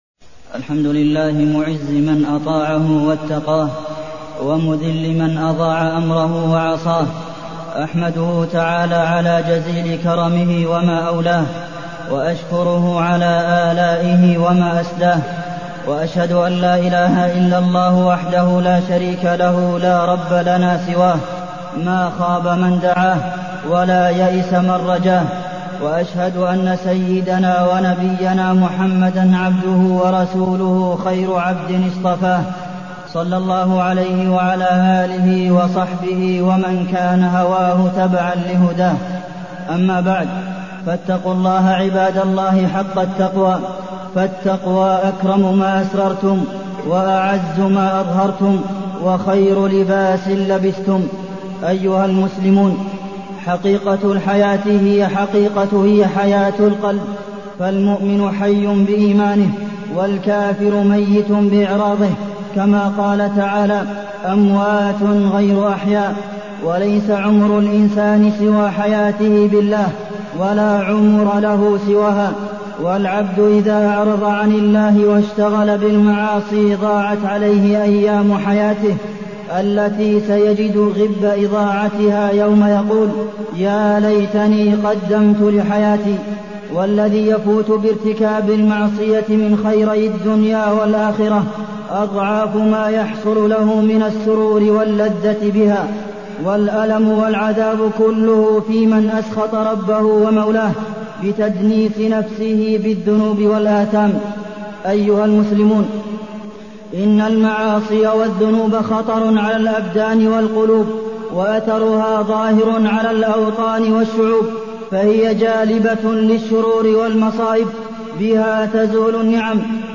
تاريخ النشر ٥ ذو القعدة ١٤٢٠ هـ المكان: المسجد النبوي الشيخ: فضيلة الشيخ د. عبدالمحسن بن محمد القاسم فضيلة الشيخ د. عبدالمحسن بن محمد القاسم التقوى ومراقبة الله